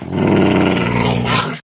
mob / wolf / growl3.ogg
growl3.ogg